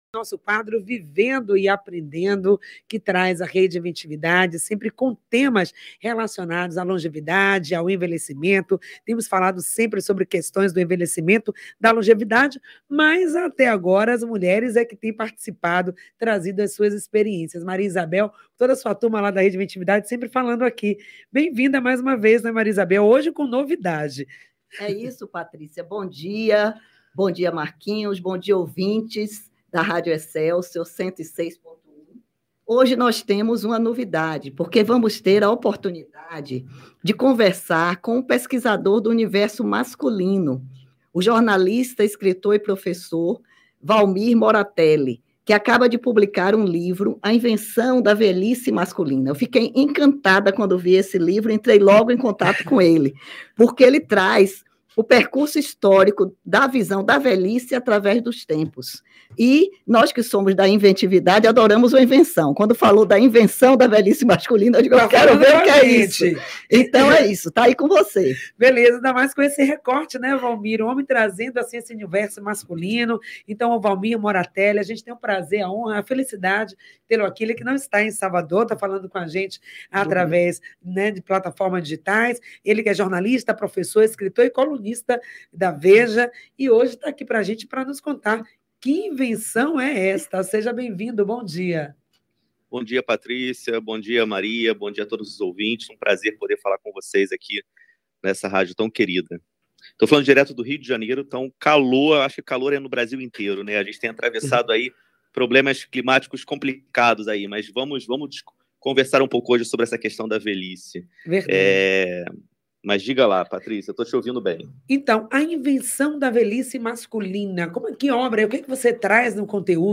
Tema da entrevista:A velhice masculina.